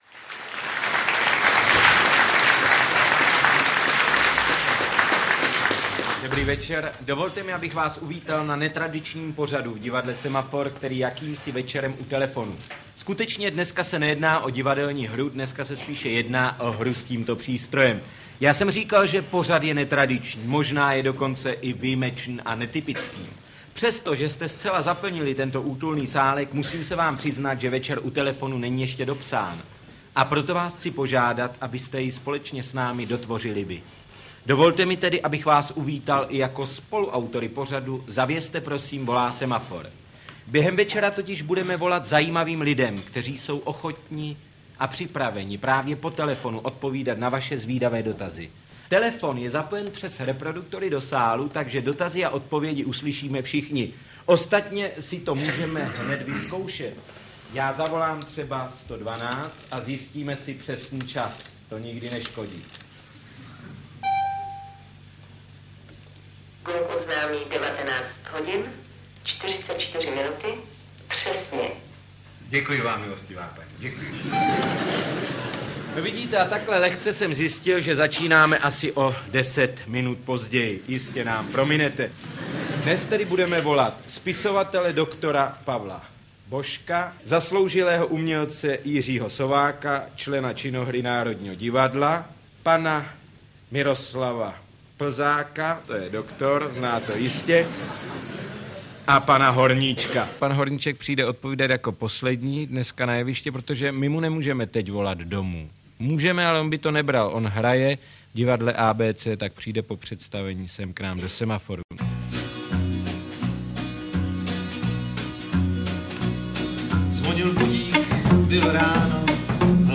Interpret:  Miloslav Šimek
Sestřih záznamu dvou pořadů Divadla Semafor potěší nejen pamětníky, kteří si pod jmény účinkujících osobností vybaví okamžitě důvěrně známé tváře z televizní obrazovky a z jeviště.